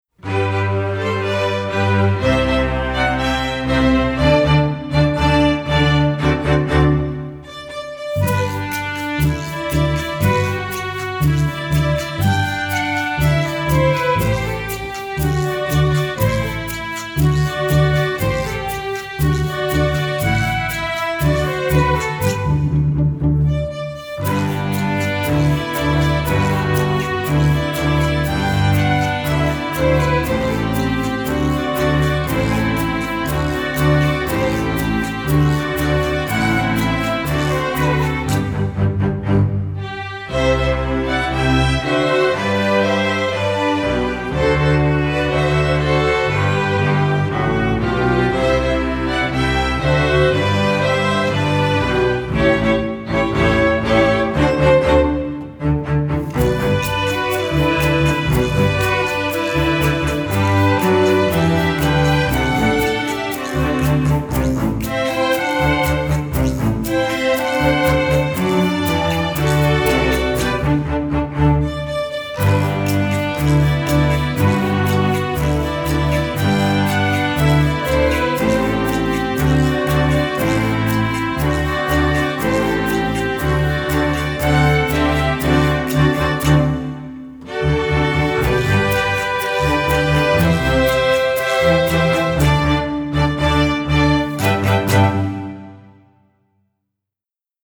folk, latin
Piano accompaniment part:
1st percussion part: